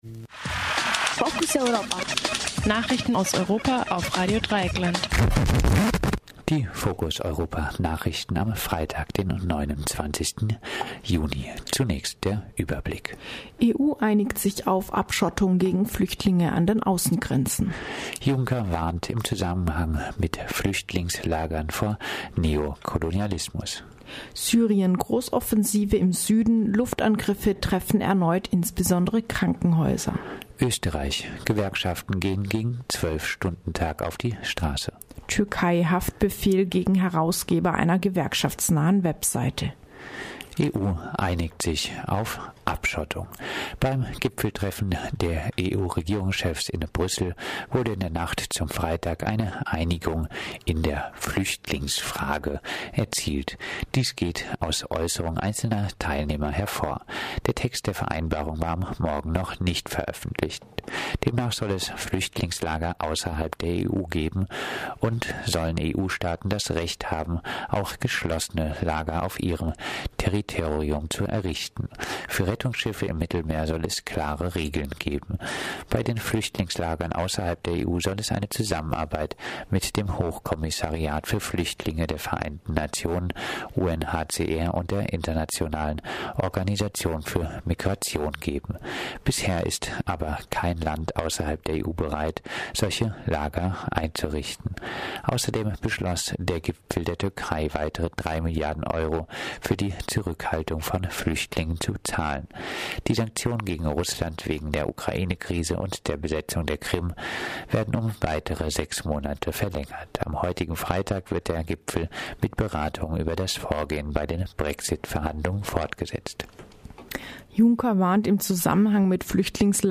Focus Europa Nachrichten vom Freitag, dem 29. Juni